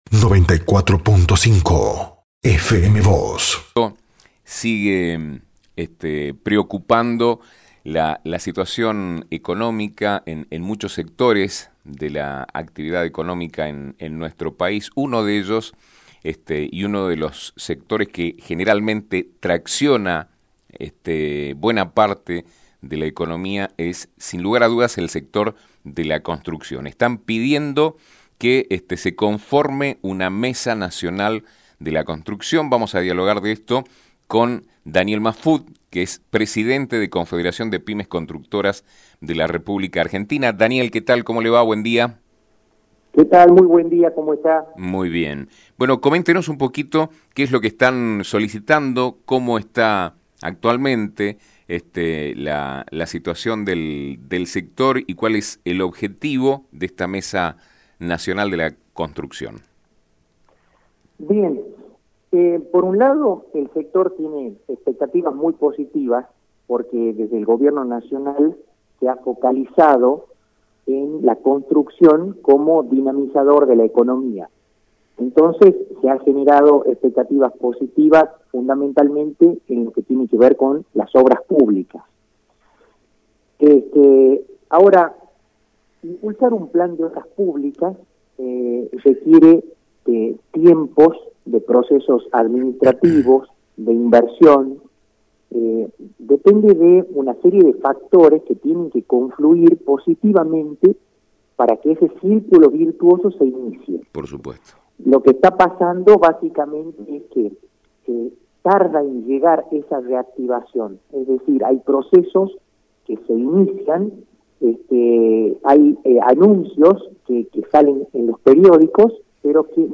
Uno de los sectores que más preocupa desde el punto de vista de la economía de nuestro país es el de la construcción, desde el cual piden que se conforme una mesa nacional a fin de luchar contra los desajustes. Al respecto, dialogó con FM Vos (94.5) y Diario San Rafael